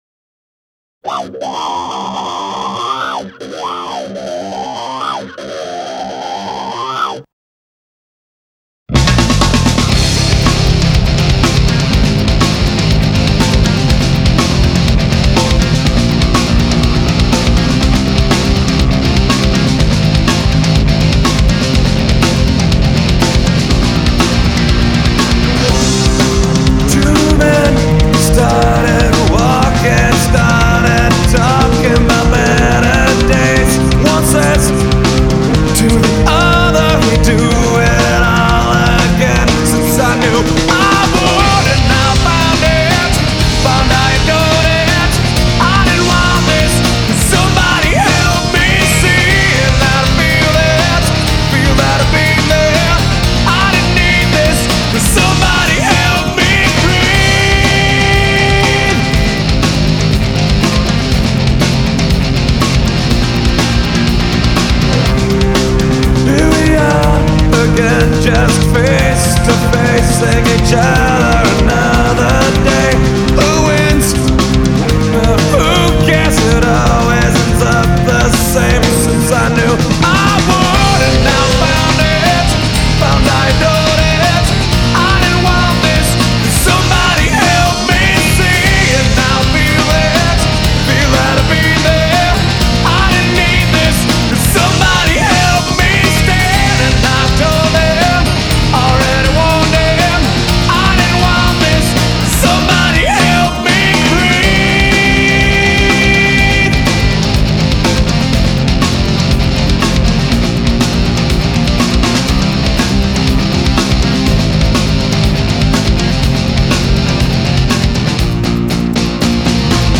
Musique diffusée: Quand Riley joue au basket.